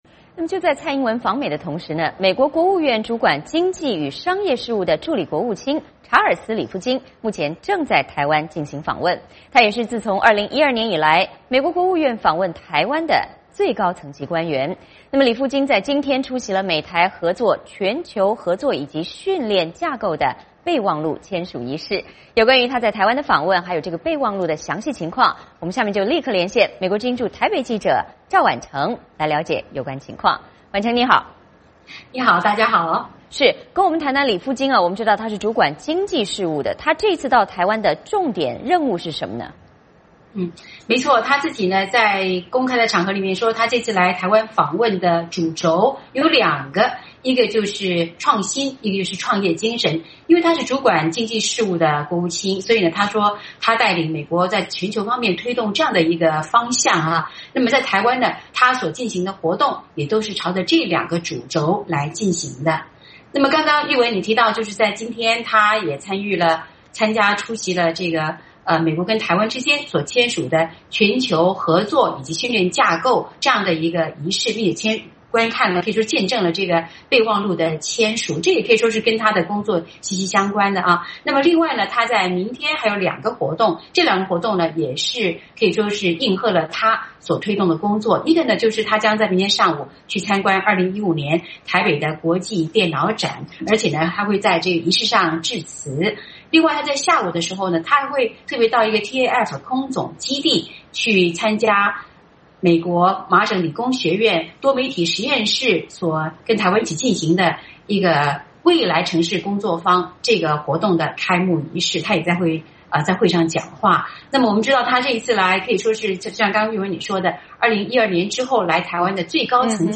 VOA连线：美国经济助卿访台，着眼美台经济合作